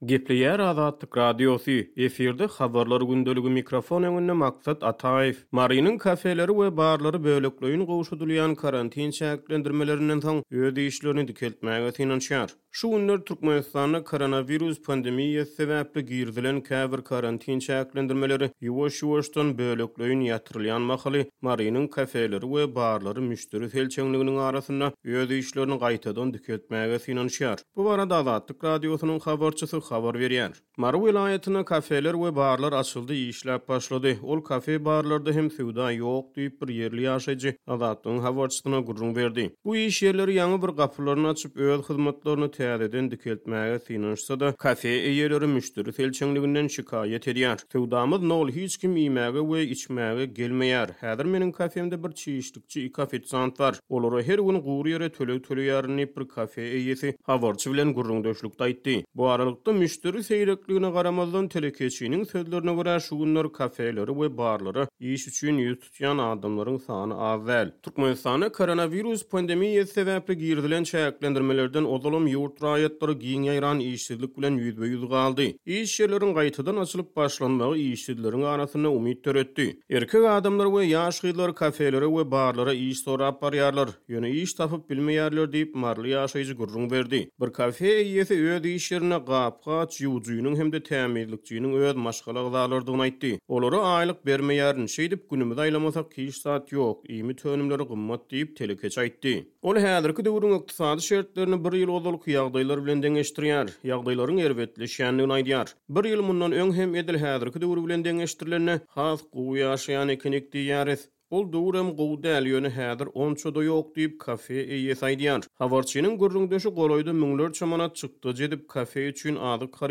Şu günler Türkmenistanda koronawirus pandemiýasy sebäpli girizilen käbir karantin çäklendirmeleri ýuwaş-ýuwaşdan, bölekleýin ýatyrylýan mahaly, Marynyň kafeleri we barlary müşderi selçeňliginiň arasynda öz işlerini gaýtadan dikeltmäge synanyşýar. Bu barada Azatlyk Radiosynyň habarçysy habar berýär.